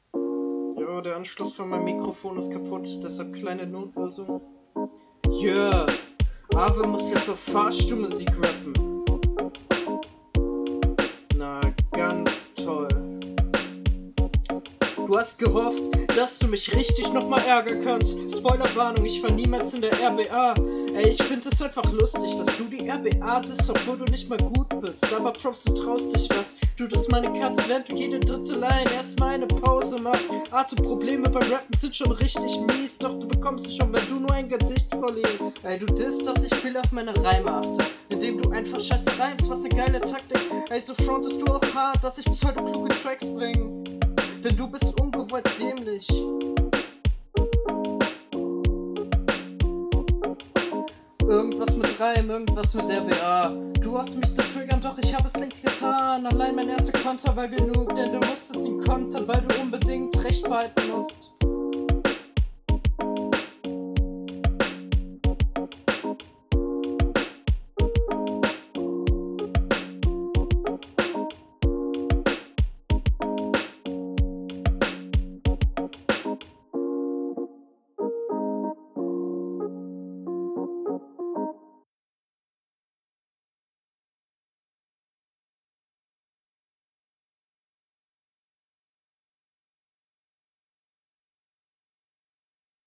Soundbild ist noch schlechter geworden ... sad. Flow ist wesentlich routinierter als in der HR.